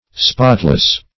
Spotless \Spot"less\, a.